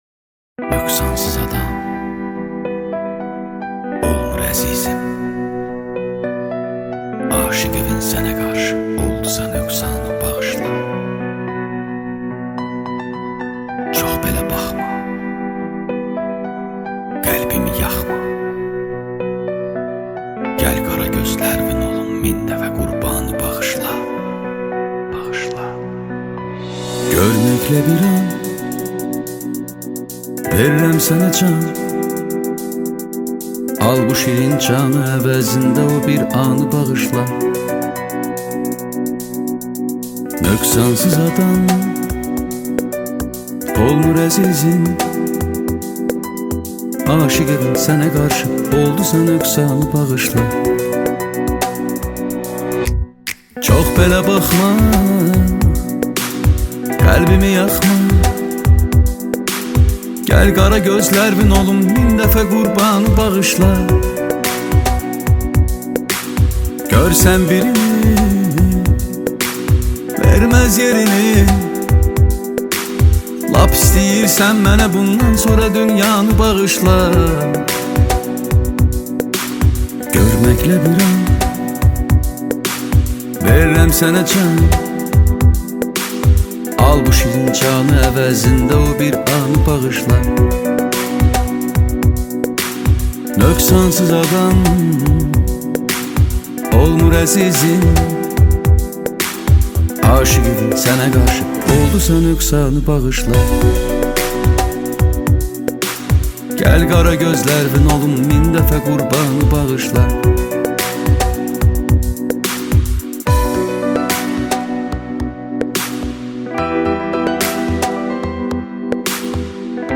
En Güzel Pop Müzikler Türkçe + Yeni şarkılar indir